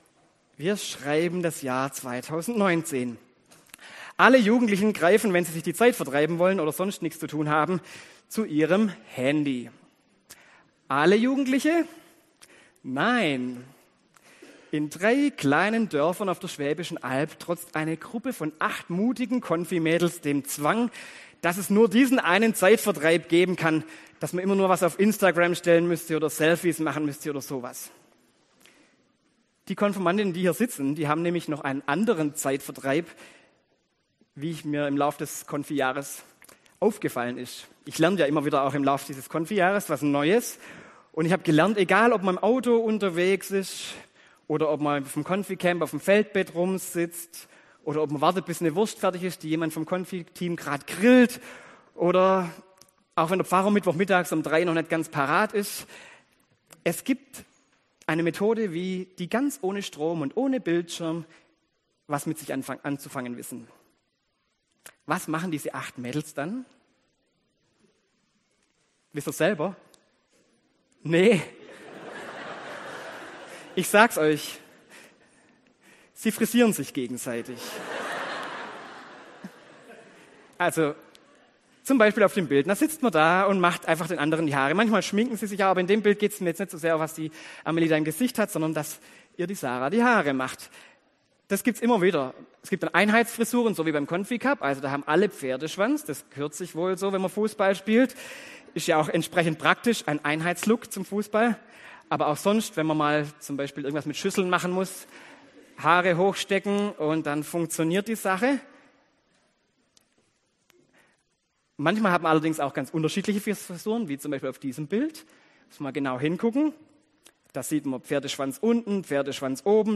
Predigt zur Konfirmation: Glaube… eine haarige Sache